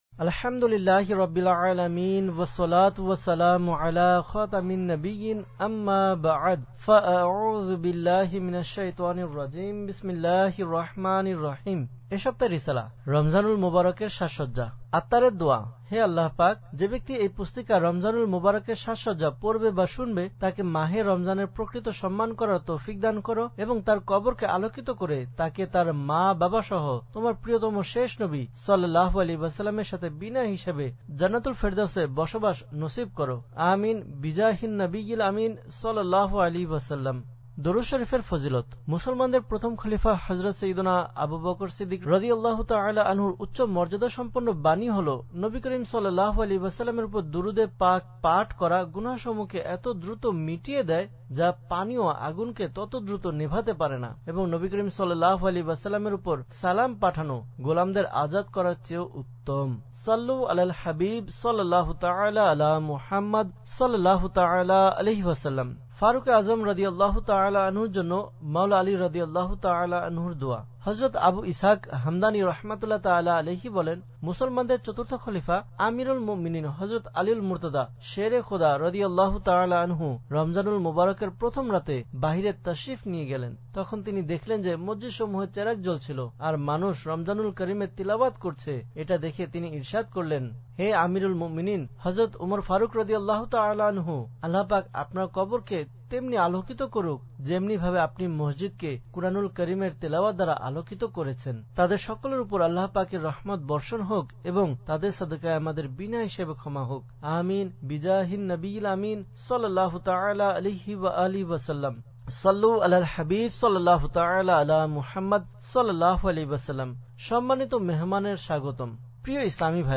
Audiobook - রমযানুল মুবারকের সাজসজ্জা (Bangla)